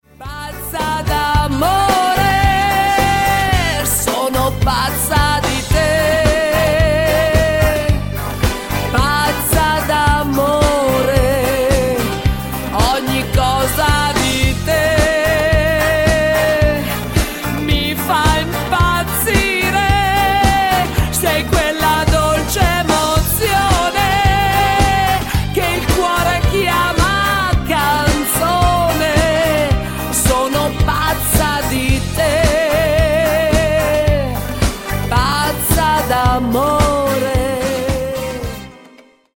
MODERATO  (4.31)